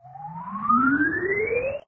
object_grow_2.ogg